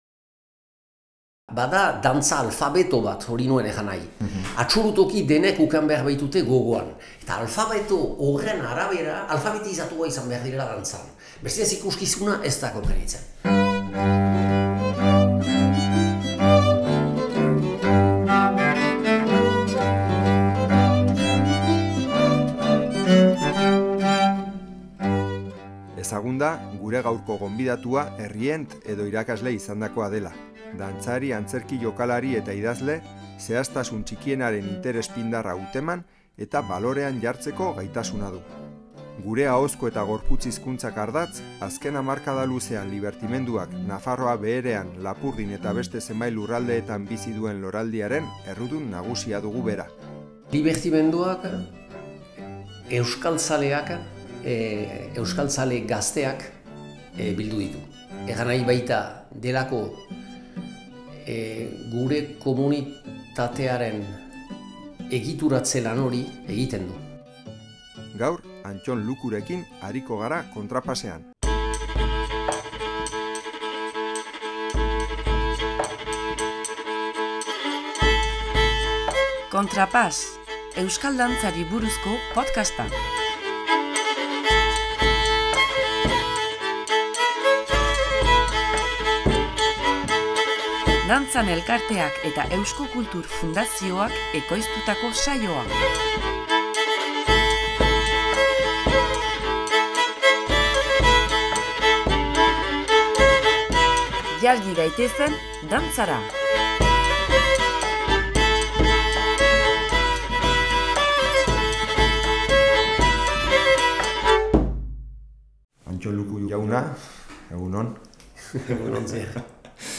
Sarrerako doinua: Mazurka.